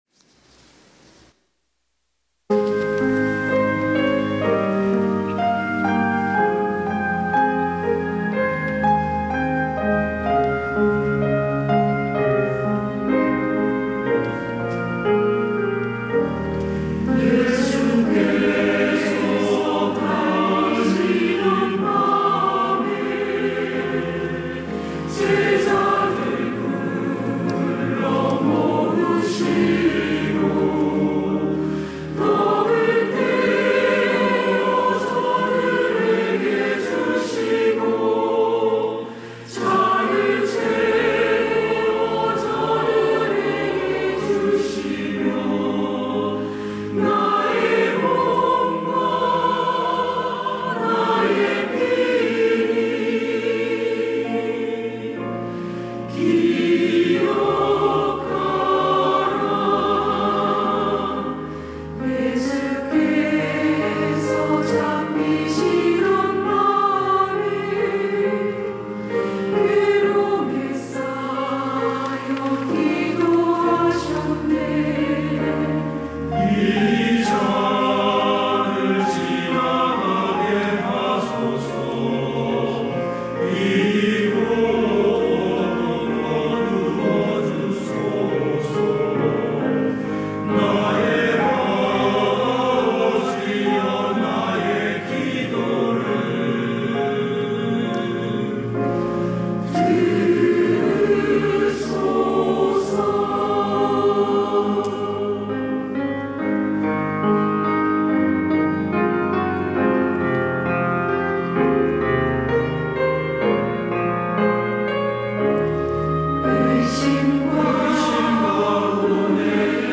시온(주일1부) - 기억하라
찬양대 시온